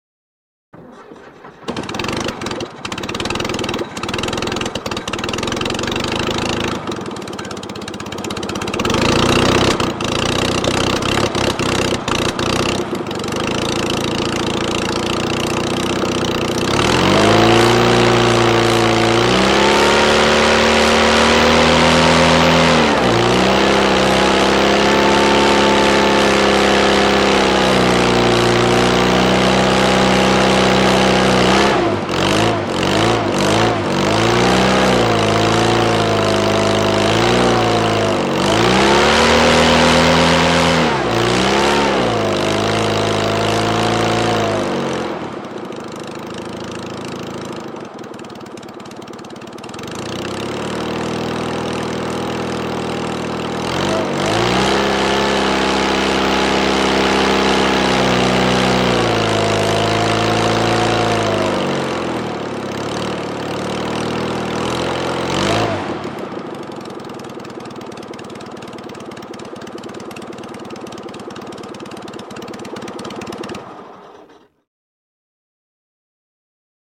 Mini Hovercraft; Run ( Ext. ); Mini Hovercraft Idles, Revs, And Then Finally Switches Off.